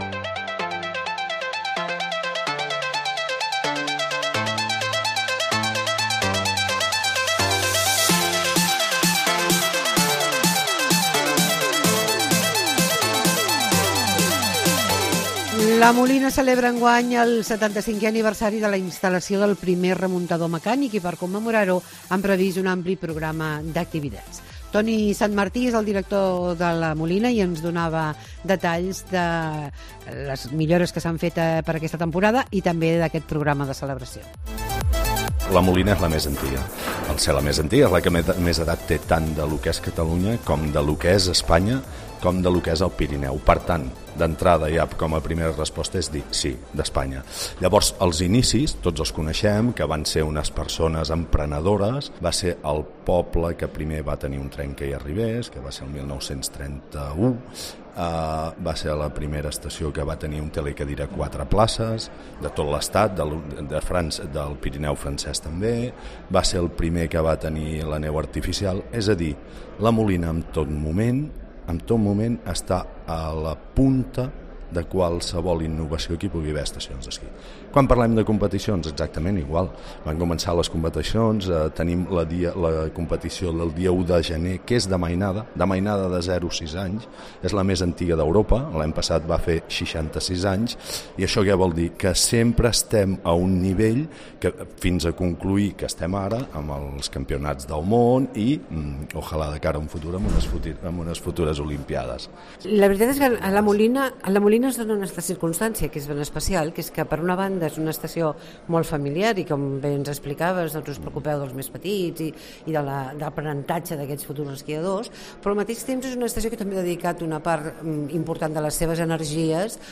Tira Milles La Molina celebra el seu 25è aniversari. Entrevista